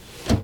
Door_close.L.wav